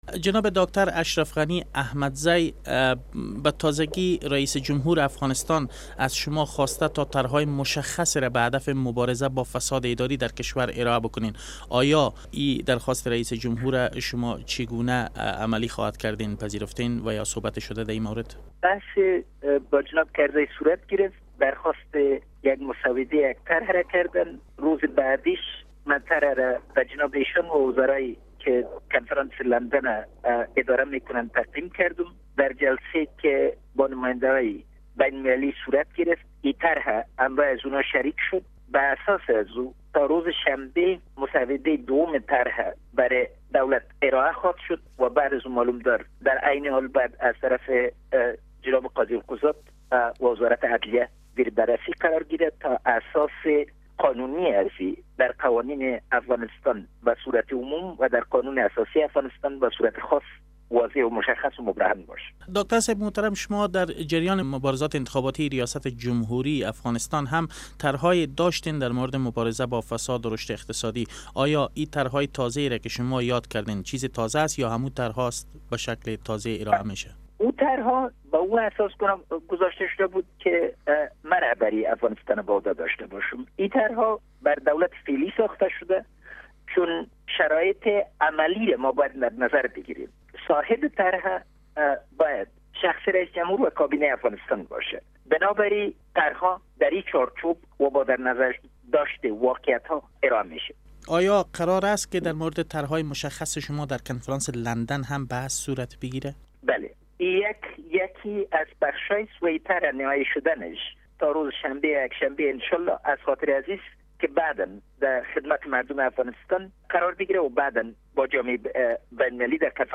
مصاحبه با داکتر اشرف غنی احمدزی در رابطه به طرح های تازه یی جلوگیری از فساد در افغانستان